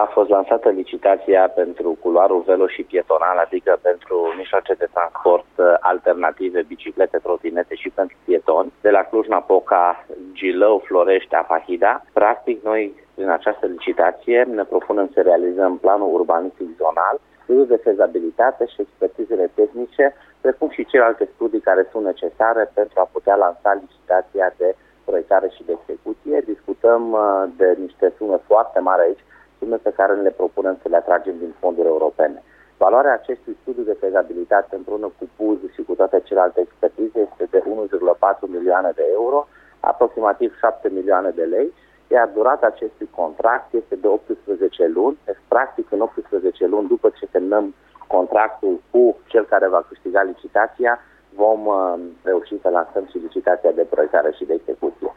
Viceprimarul Dan Tarcea: